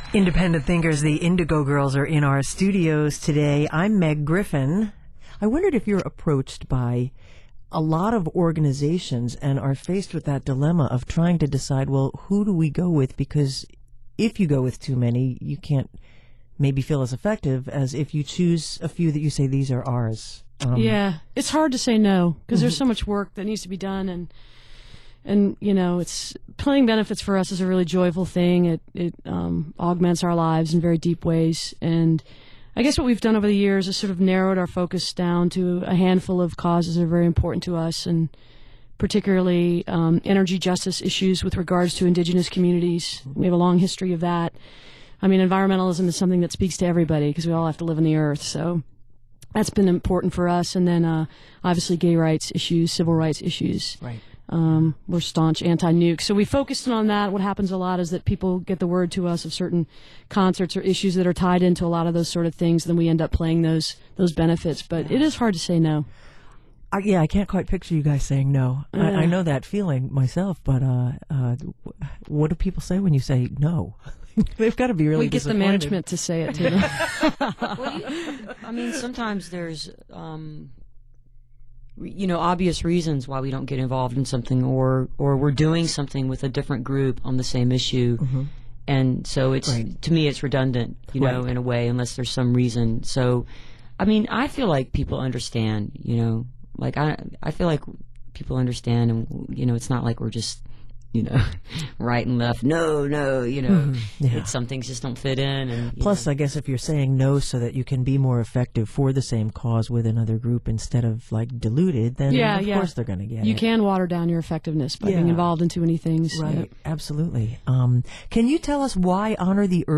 (acoustic duo show)
07. interview (3:41)